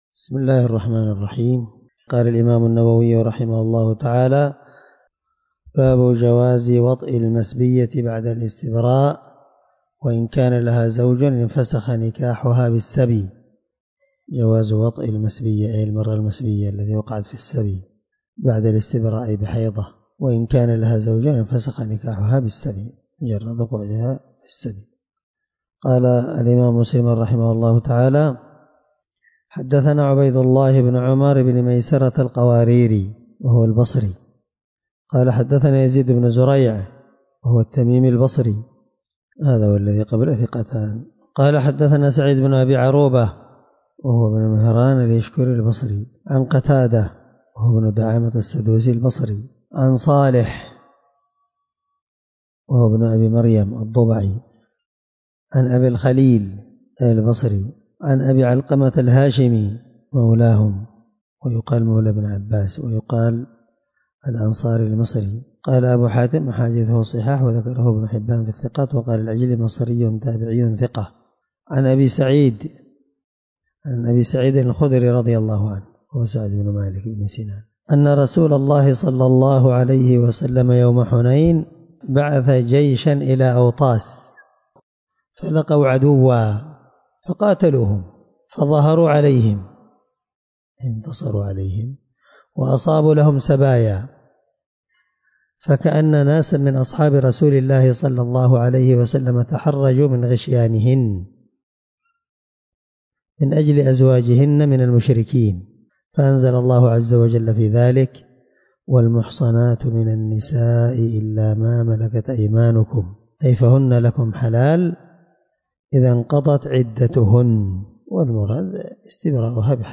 الدرس9 من شرح كتاب الرضاع حديث رقم(1456) من صحيح مسلم